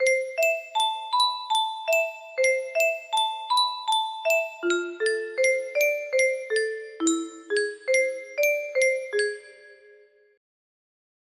Clone of Sankyo Music Box - Notre Dame Victory March IL music box melody